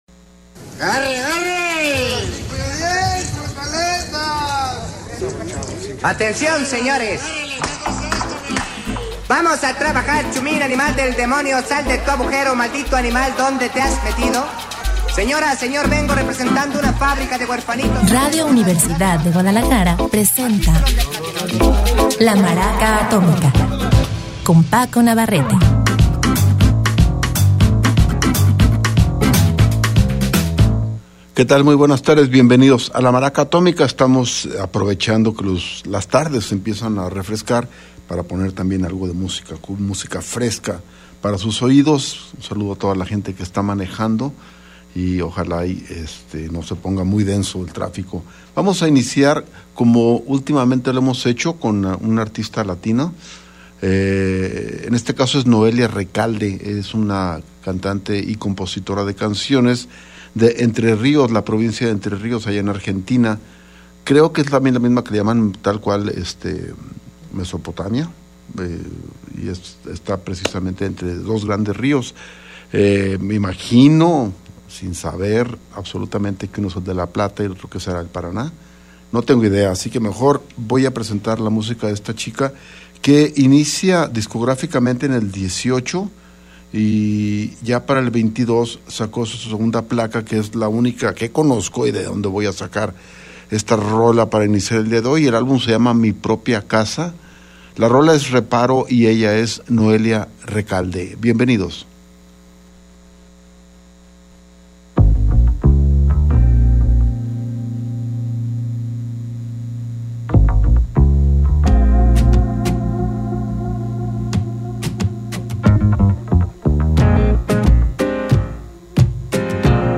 tenemos musica fresca como la lluvia